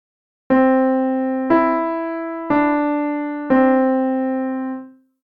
We will start with melodies using just the first three notes of the major pentatonic scale, do, re and mi.
(key: C Major)